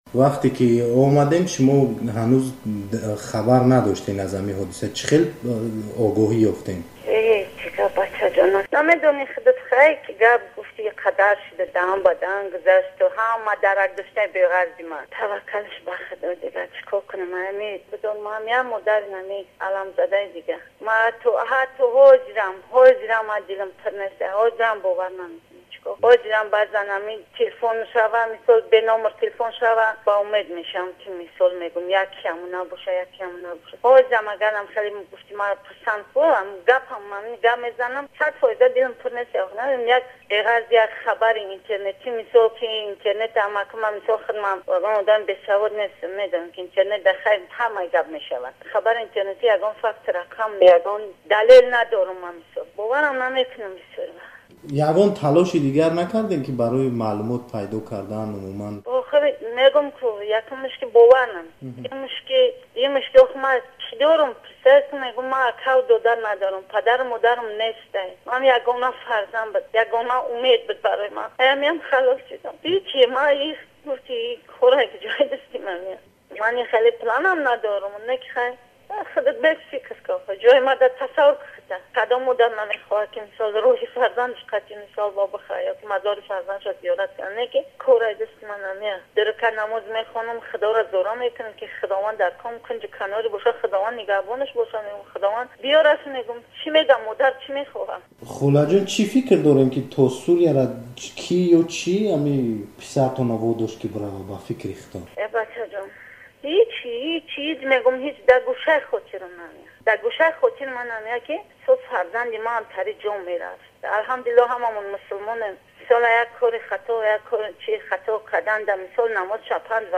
Мусоҳиба